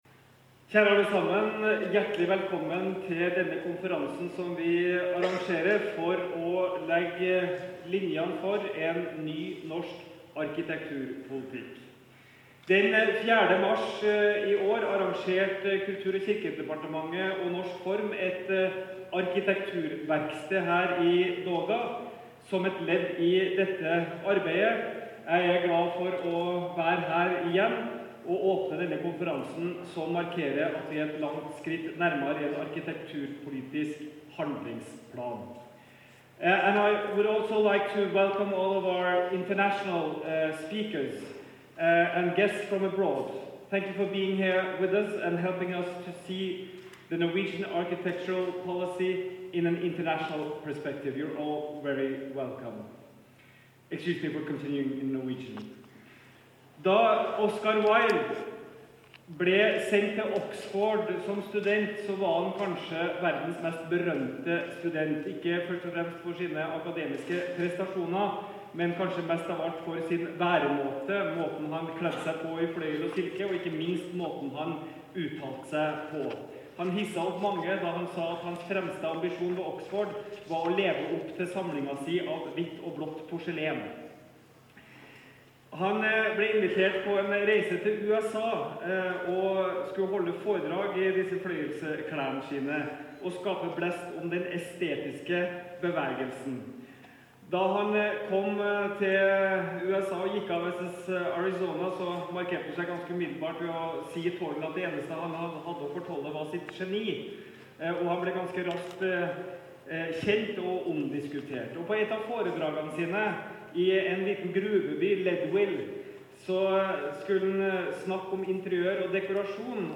Det var Kultur- og kirkedepartementet og Norges forskningsråd som inviterte til konferansen på DogA 26. og 27. mai, mens Norsk Form var arrangør. Kulturminister Trond Giske åpnet konferansen, og hans åpningstale kan du høre her.